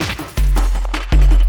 53LOOP02SD-R.wav